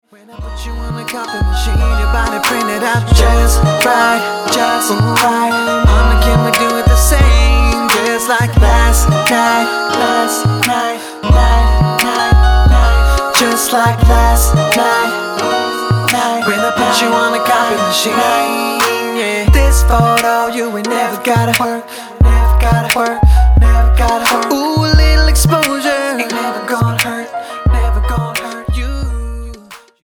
NOTE: Vocal Tracks 1 Thru 9